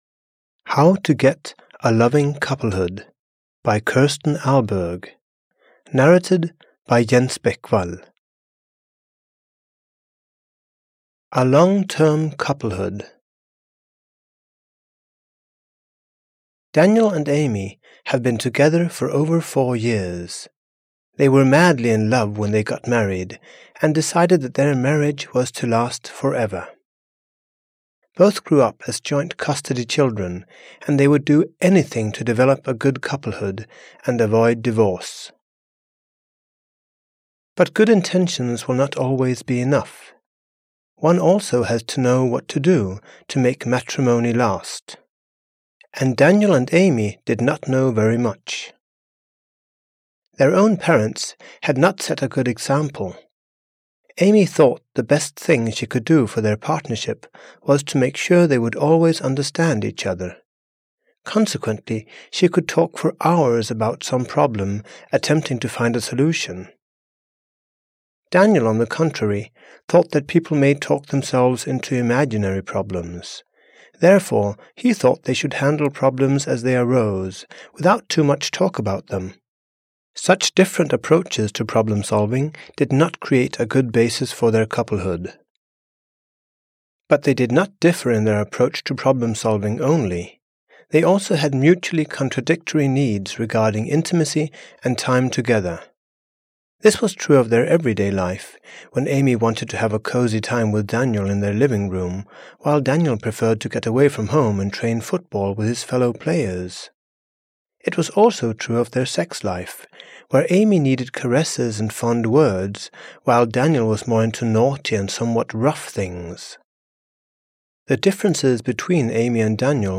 How to Get a Loving Couplehood (EN) audiokniha
Ukázka z knihy